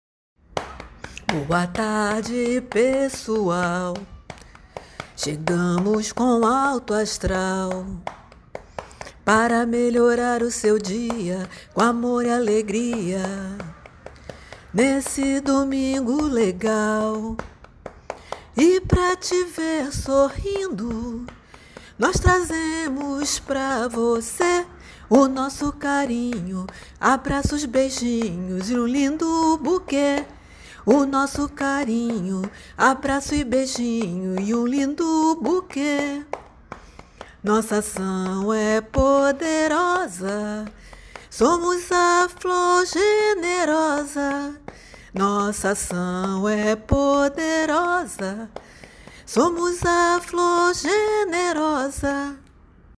compôs rapidinho o som e o ritmo adequados para uma marchinha fácil e graciosa.